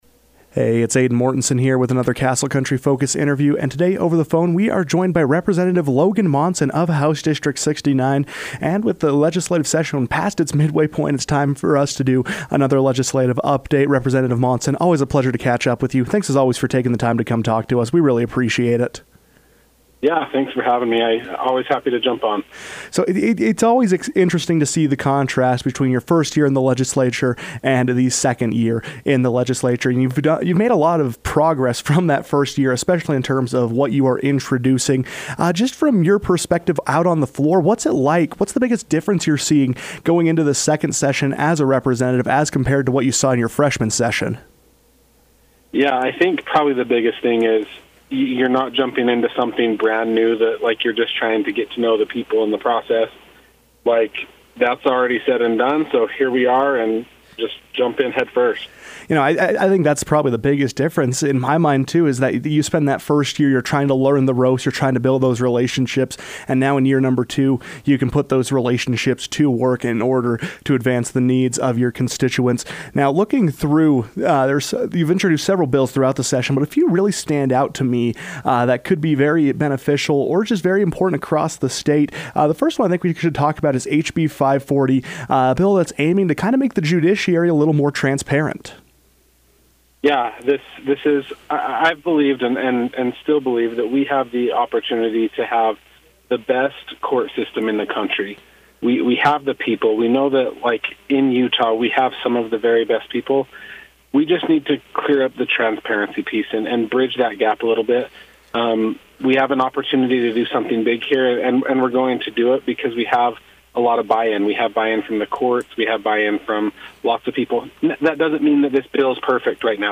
As the Utah State Legislature enters its final stretch, HD69 Rep. Logan Monson joined the KOAL newsroom to discuss his priorities thus far in the session, as well as the major difference between his first year in the body and now.